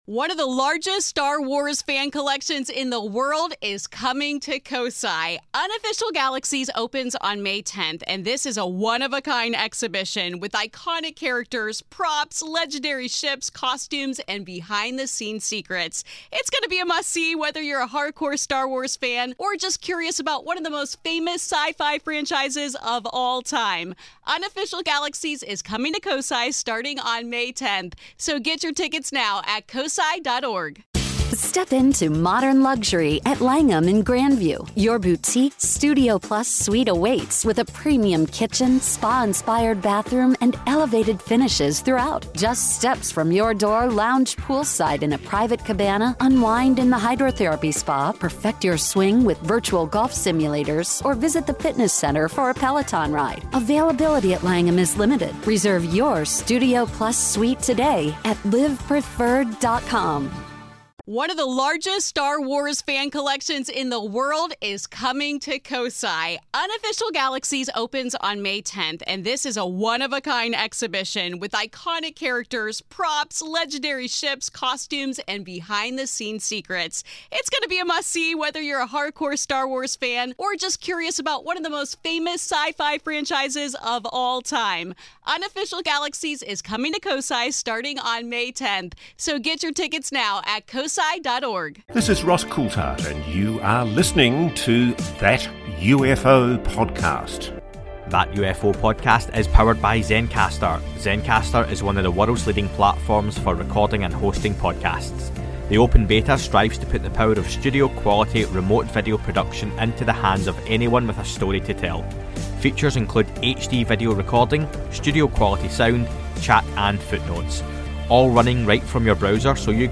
Listener questions